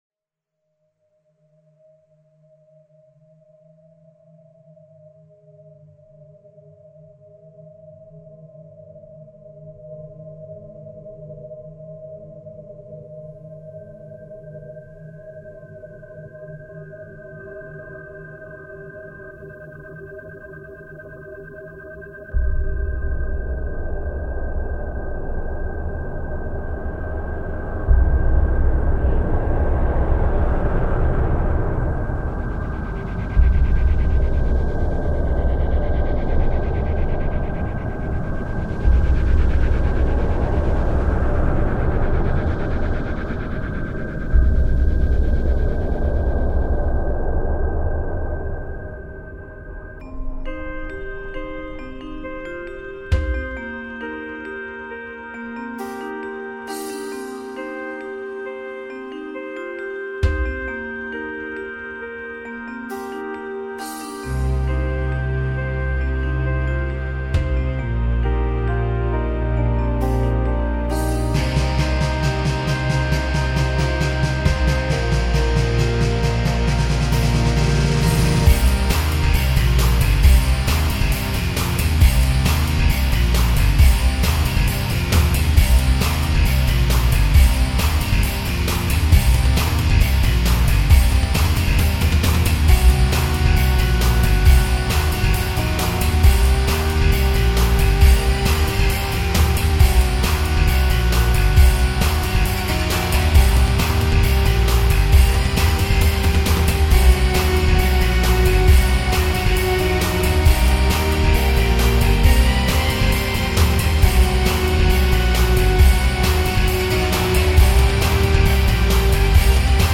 Fast Rock